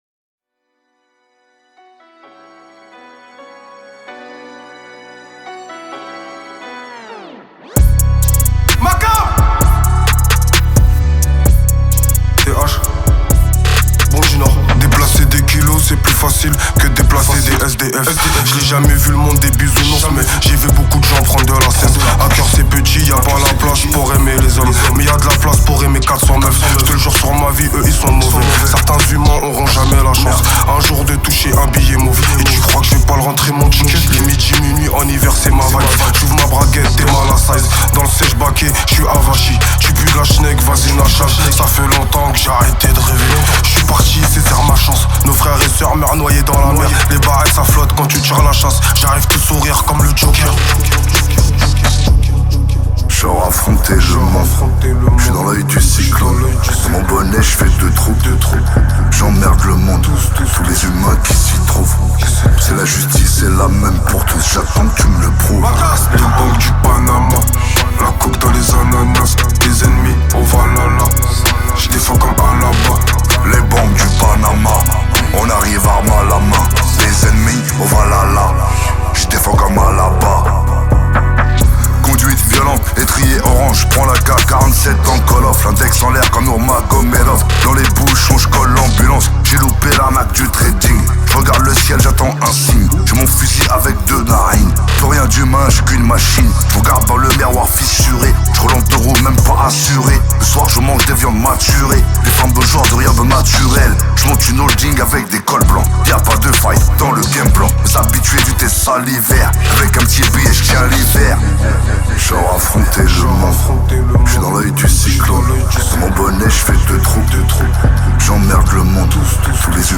Genres : french rap, pop urbaine